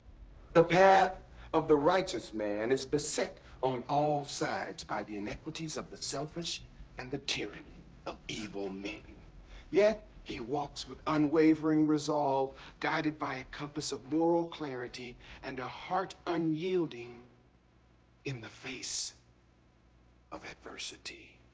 Guess which part is synthesized!